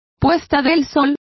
Complete with pronunciation of the translation of sunsets.